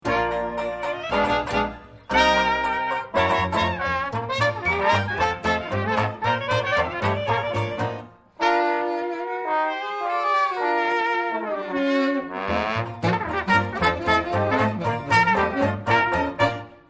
Trumpet, Alto Sax, Baritone Sax, Bass Sax,Vocals.
Clarinet, Tenor Sax.
Trombone, Percussion, Washboard.
Banjo, Guitar.
String Bass.